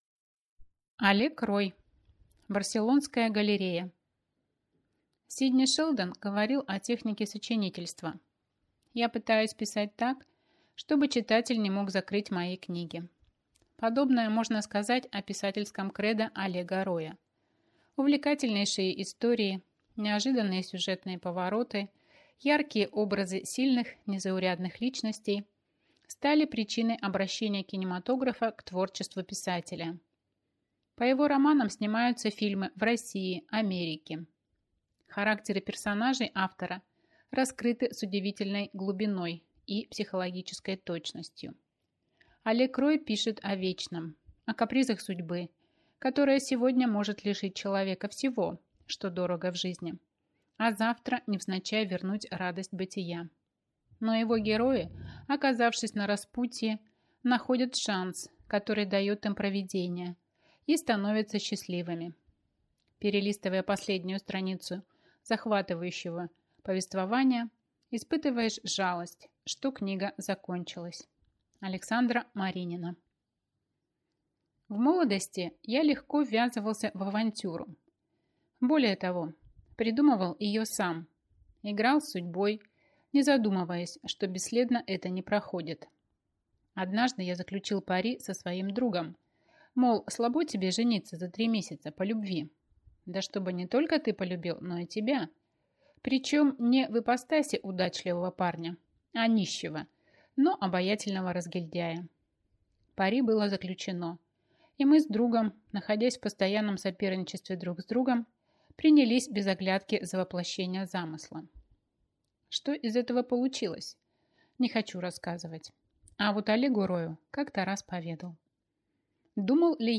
Аудиокнига Барселонская галерея | Библиотека аудиокниг